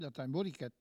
Mémoires et Patrimoines vivants - RaddO est une base de données d'archives iconographiques et sonores.
locutions vernaculaires